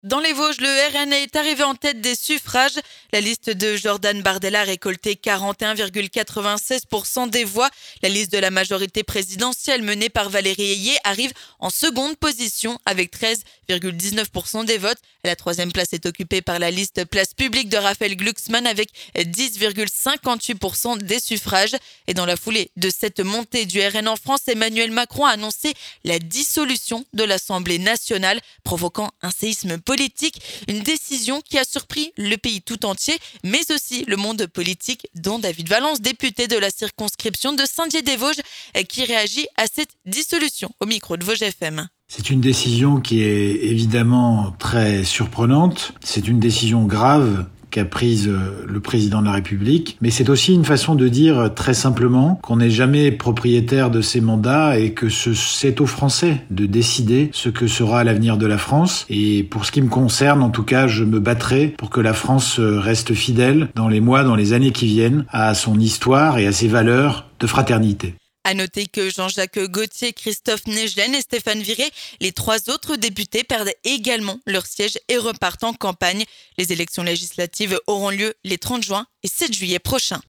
Suite à cette montée du RN, Emmanuel Macron a annoncé la dissolution de l’Assemblée nationale dimanche soir provoquant un séisme politique. Une décision qui a surpris le pays tout entier et le monde politique dont David Valence Député de la circonscription de Saint-Dié-des-Vosges qui réagit à cette dissolution au micro de Vosges FM.